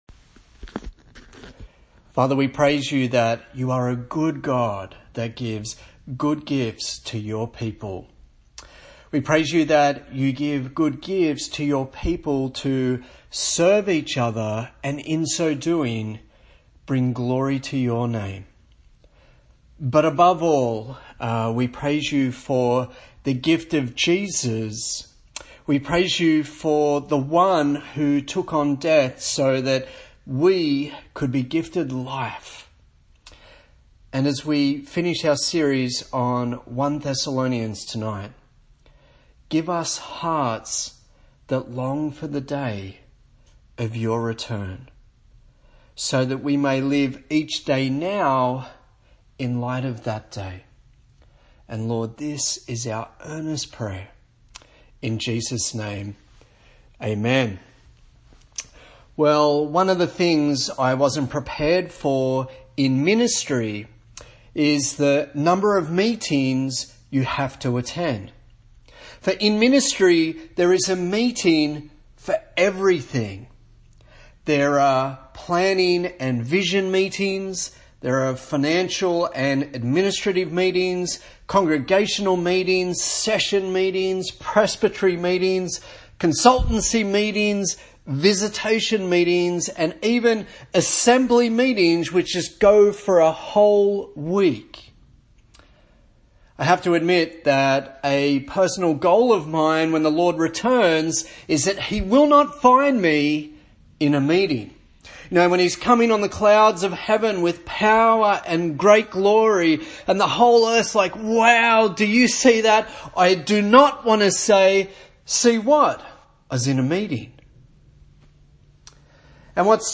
Holy Kisses and Stuff Preacher